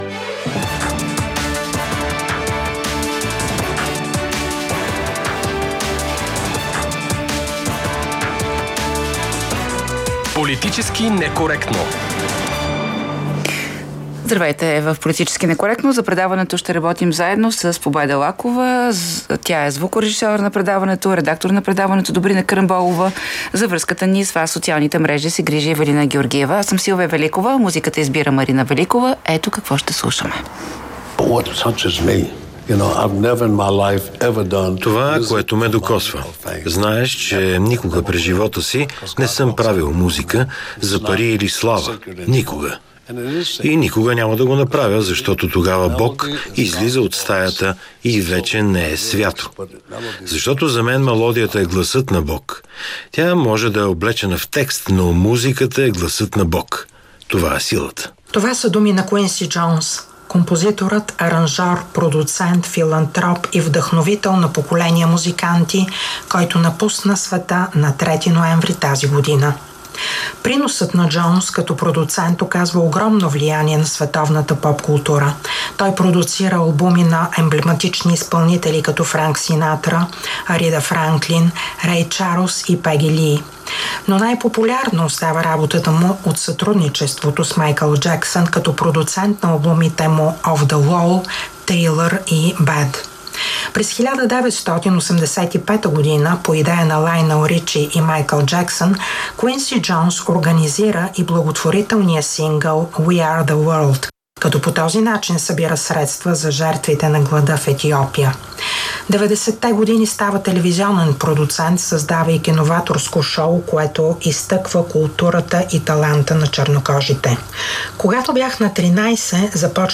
▪ Гост е социологът Андрей Райчев, който още преди месеци прогнозира, че победа на Тръмп е равна на нормализация, която ще усетим включително и у нас.